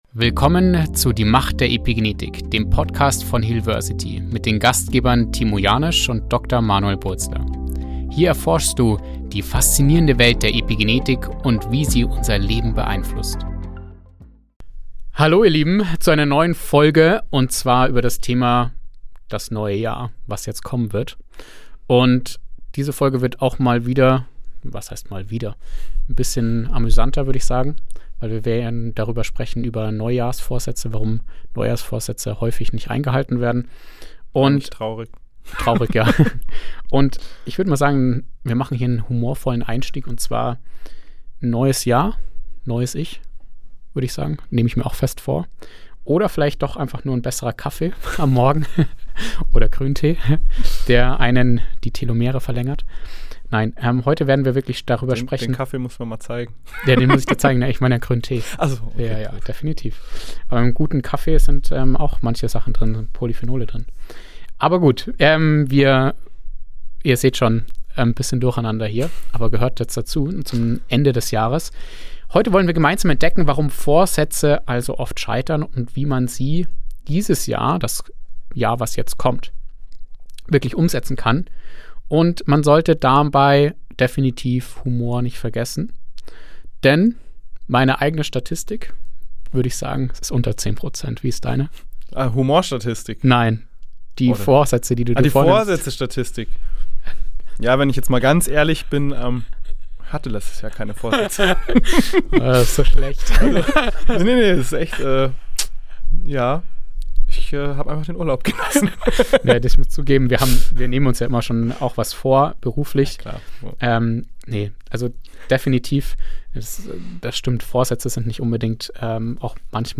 Beide Moderatoren blicken auf das vergangene Jahr zurück und teilen ihre eigenen Erfahrungen mit Veränderung und den Herausforderungen, die damit einhergehen.